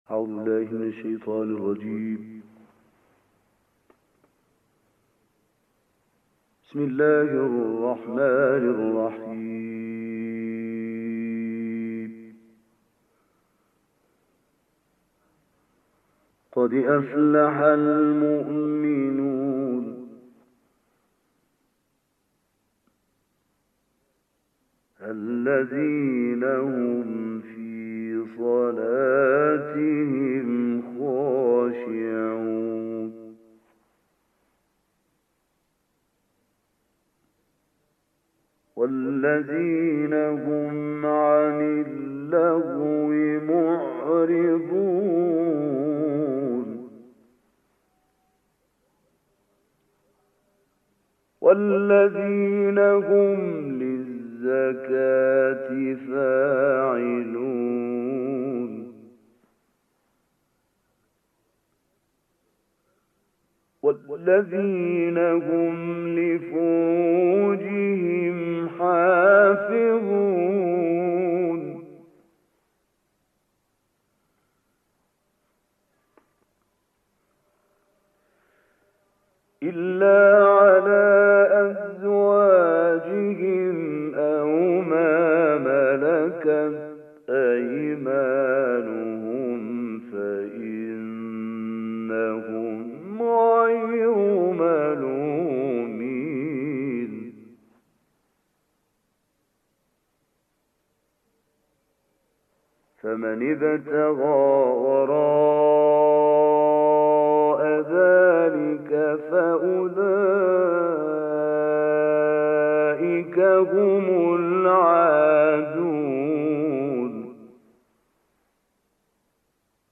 قاری گمنام
تلاوت